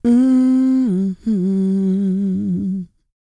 E-CROON P314.wav